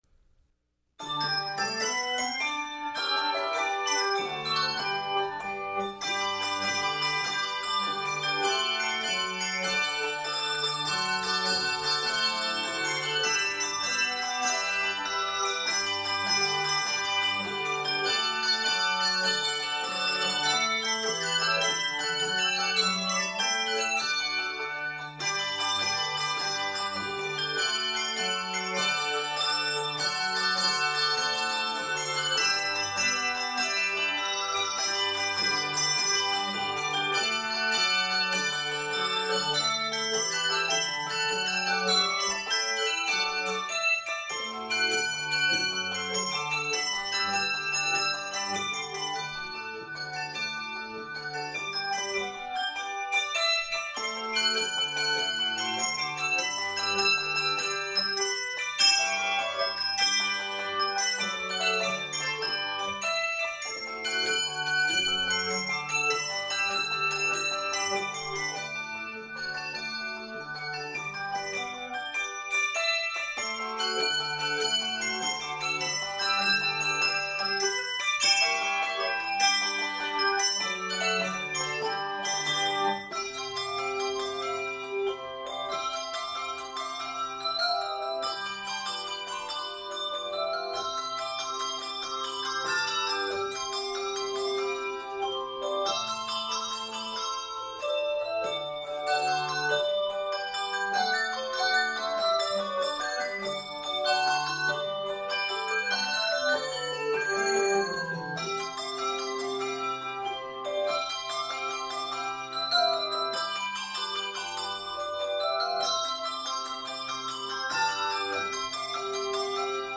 Fun and frolicsome